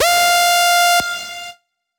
Index of /99Sounds Music Loops/Instrument Oneshots/Leads